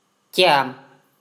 The mi gyaung (Burmese: မိကျောင်း [mḭ dʑáʊɰ̃]) or kyam (Mon: ကျာံ, [cam]
; pronounced "chyam") is a crocodile-shaped fretted, plucked zither with three strings that is used as a traditional instrument in Burma.